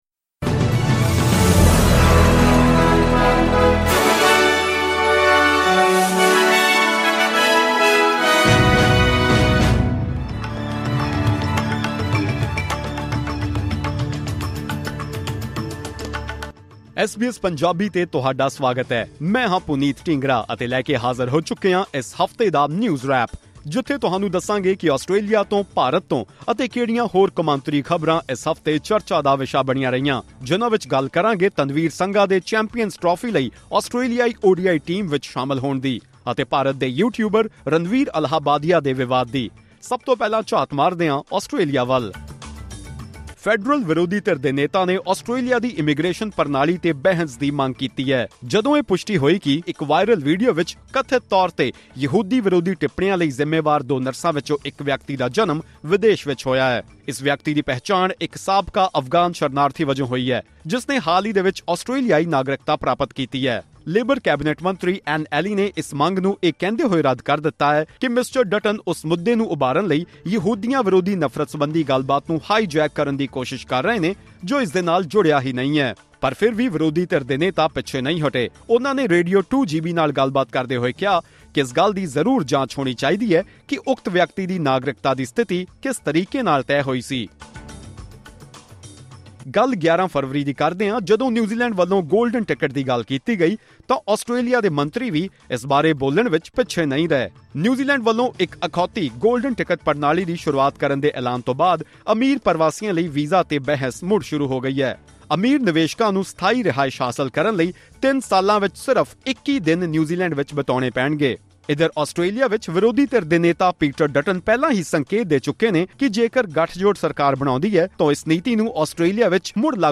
In this weekly bulletin, we discuss the news from Australia, India, and other parts of the world that have been the talk of the week. Speaking of India, the biggest headline was a comment made by podcaster Ranveer Allahbadia, which has sparked controversy across the country.